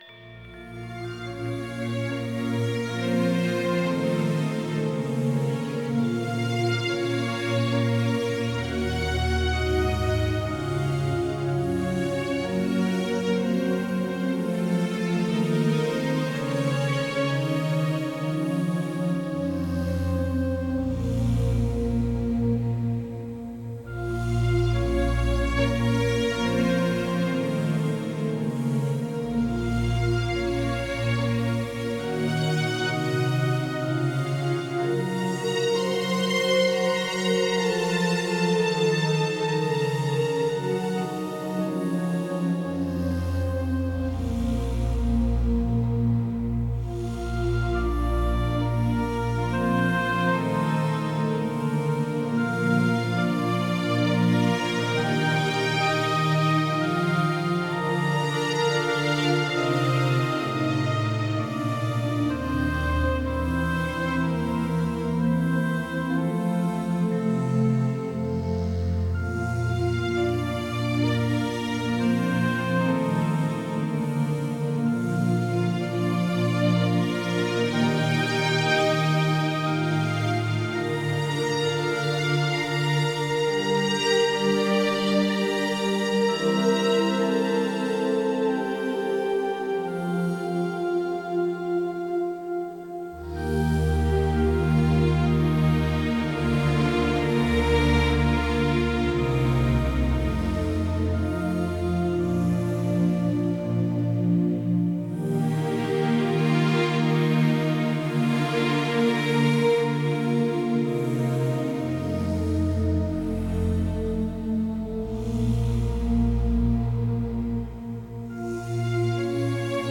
Жанр: Soundtrack.